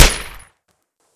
sfx_gunshot6.wav